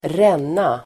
Uttal: [²r'en:a]